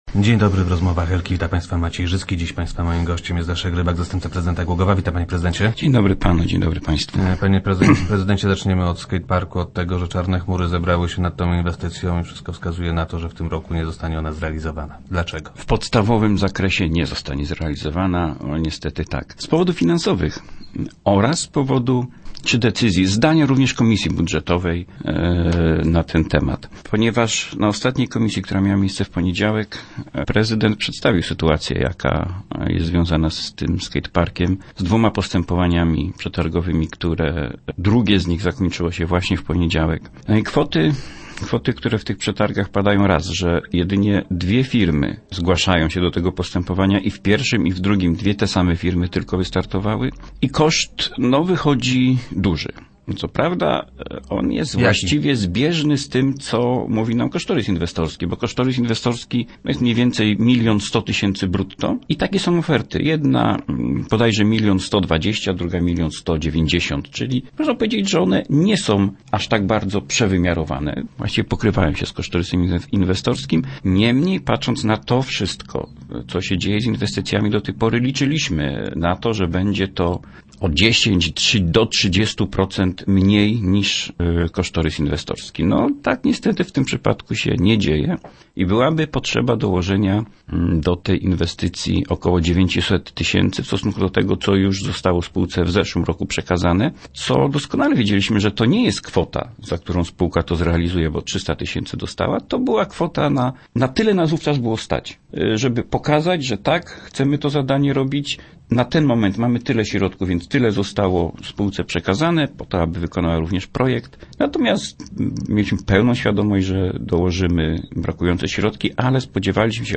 Dłużej trzeba będzie także poczekać na fontannę na osiedlu Kopernika. - Musimy powtórzyć przetargi na te zadania – informuje Leszek Rybak, zastępca prezydenta Głogowa.